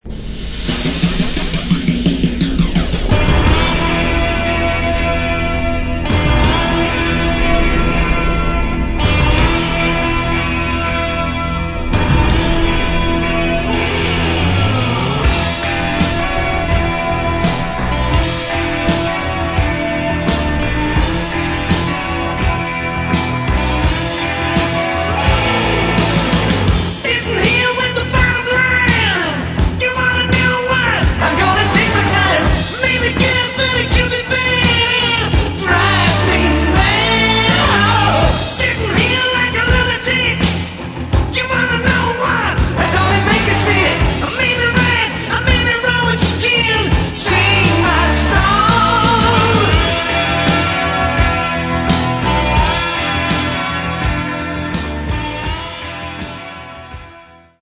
Рок
Плотный роковый бас и барабаны
клавишные
отличительные гитары